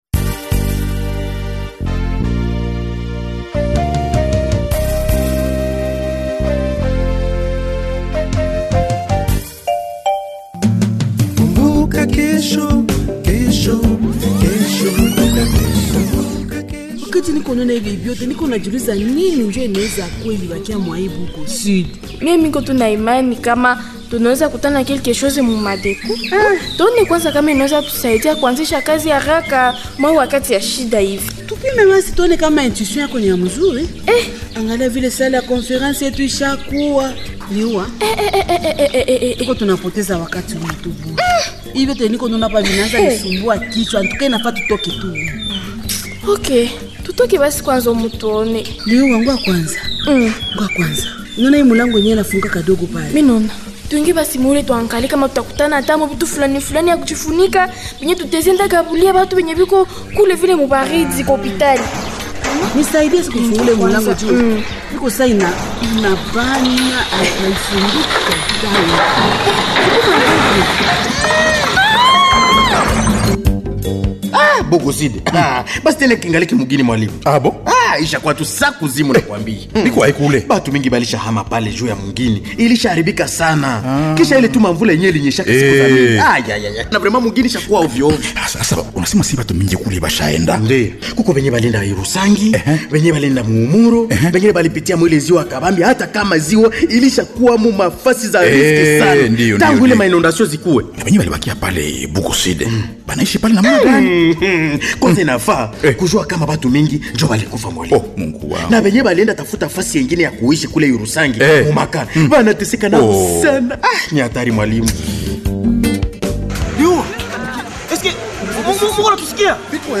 Voici le 972e numéro du feuilleton Kumbuka Kesho du 02 au 08 février 2026